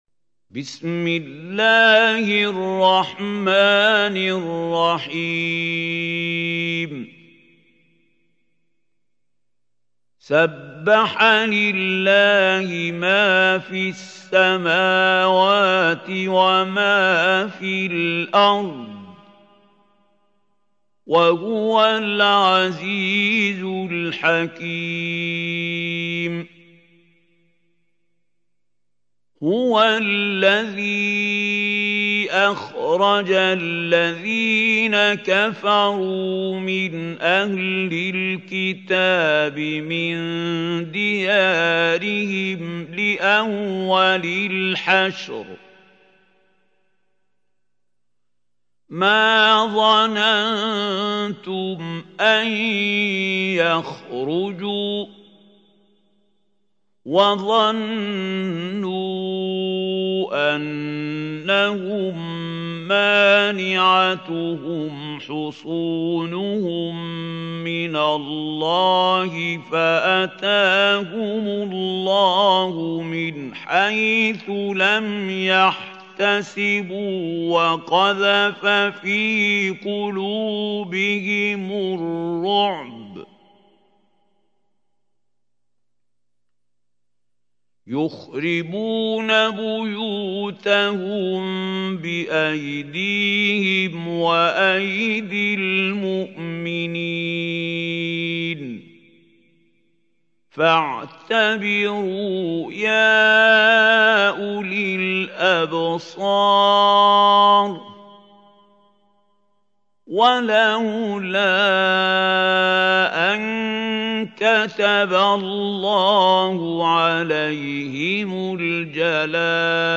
سورة الحشر | القارئ محمود خليل الحصري